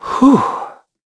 Evan-Vox_Sigh.wav